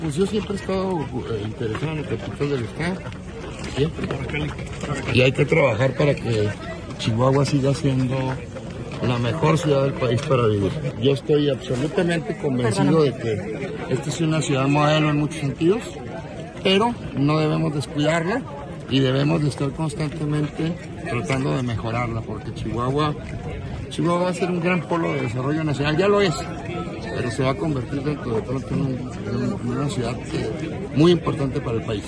El fiscal general César Jáuregui Moreno fue interrogado sobre su posible aspiración a contender por la alcaldía de la ciudad de Chihuahua en las elecciones de 2027, debido a que su nombre aparece entre los funcionarios públicos de la entidad más probables de buscar una candidatura.